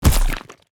body_hit_finisher_23.wav